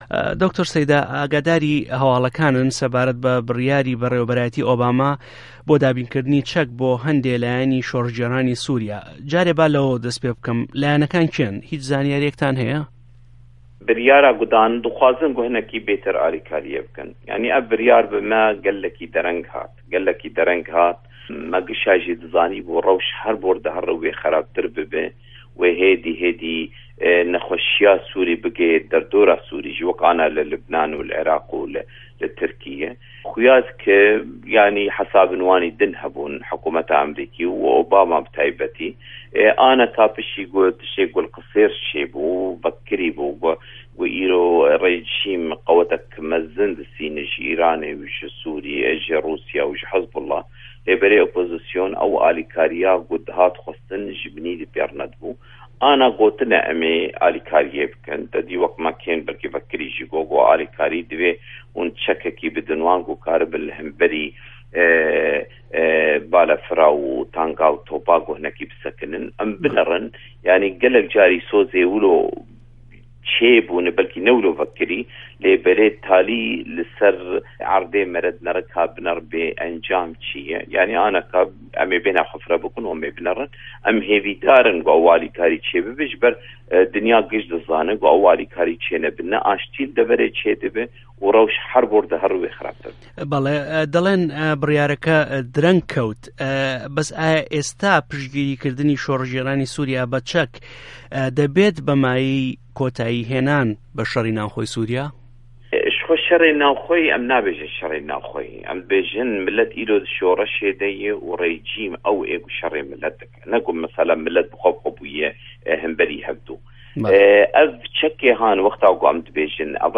وتووێژی عه‌بدولباست سه‌یدا